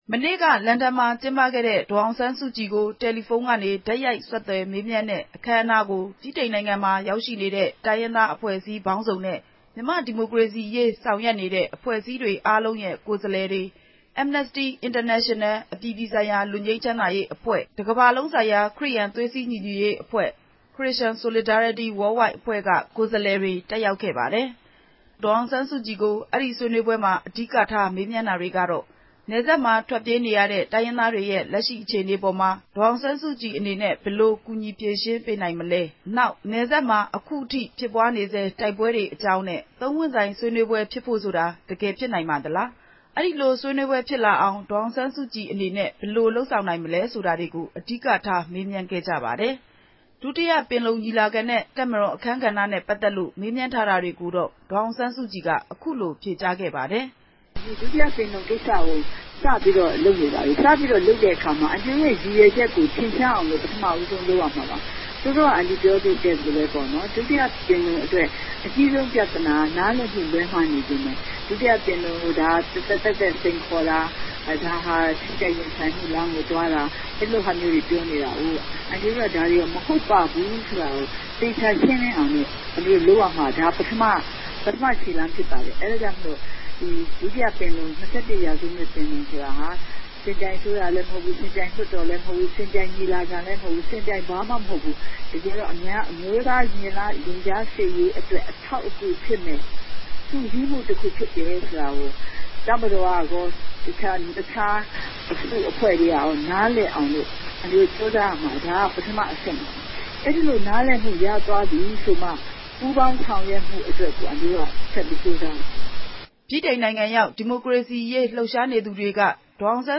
မနေ့က နေ့လယ်ခင်းက ပြုလုပ်ခဲ့တဲ့ ဗြိတိန်နိုင်ငံ လန်ဒန်အခြေစိုက် မြန်မာ့အရေး ဆောင်ရွက်နေတဲ့ အဖွဲ့အစည်း ၁၆ ဖွဲ့နဲ့ တယ်လီဖုန်းကတဆင့် တိုက်ရိုက် မေးမြန်းဆွေးနွေးခန်းမှာ ဒုတိယပင်လုံ သဘောထားရပ်တည်ချက်၊ တပ်မတော်အခန်းကဏ္ဍ စတာတွေနဲ့ ပတ်သက်လို့ ဒေါ်အောင်ဆန်းစုကြည်က အခုလို ဖြေကြားလိုက်တာ ဖြစ်ပါတယ်။